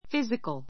physical A2 fízikəl ふィ ズィカ る 形容詞 ❶ 身体の, 肉体の physical exercise physical exercise 体操, 運動 a physical checkup a physical checkup 健康診断 しんだん ❷ 物質（界）の, 自然（界）の; 物理（学）の physical science physical science 自然科学, 物理学